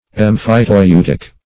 Search Result for " emphyteutic" : The Collaborative International Dictionary of English v.0.48: Emphyteutic \Em`phy*teu"tic\, a. [L. emphyteuticus.] Of or pertaining to an emphyteusis; as, emphyteutic lands.